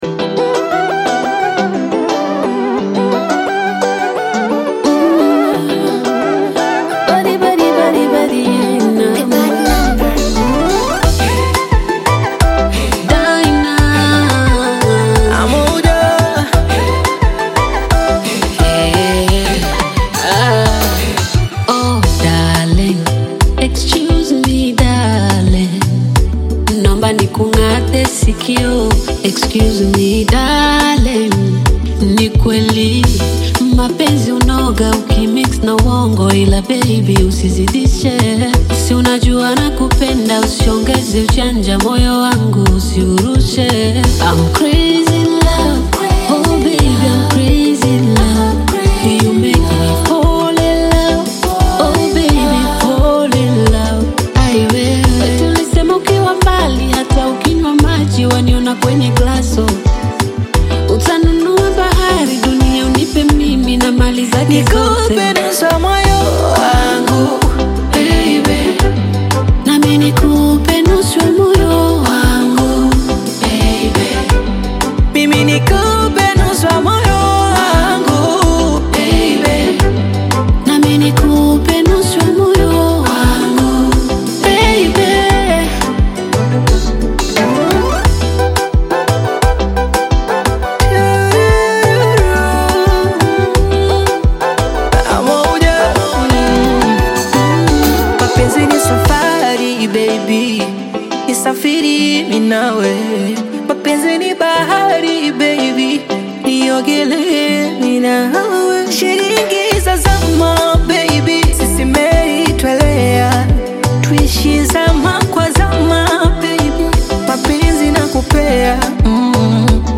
vibrant Afro-Beat single
Genre: Bongo Flava